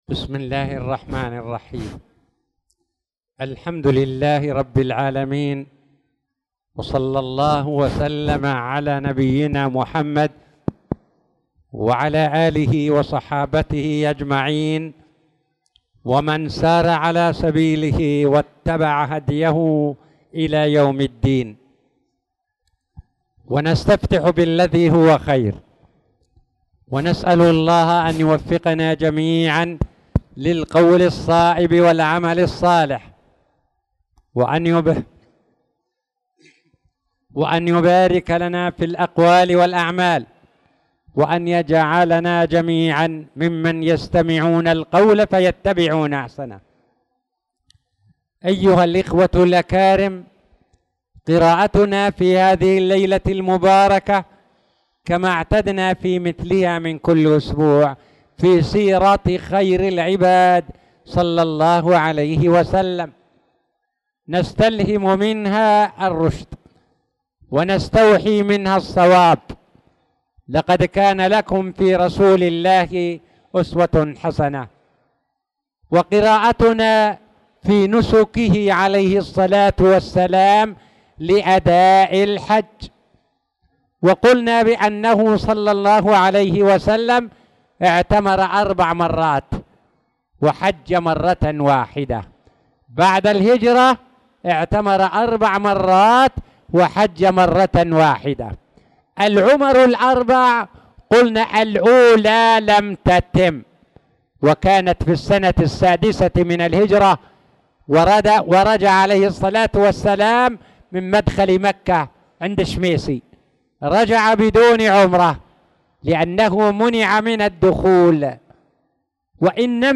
تاريخ النشر ١٢ شعبان ١٤٣٧ هـ المكان: المسجد الحرام الشيخ